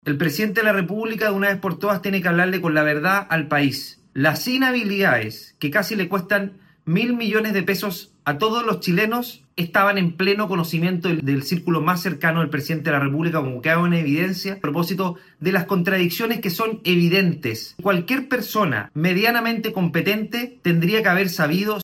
Andrés Longton, diputado de RN y presidente de la Comisión, asegura que la contradicción entre versiones de gobierno dan espacio para cuestionar la versión de La Moneda, emplazando a Boric a ‘hablar con la verdad’.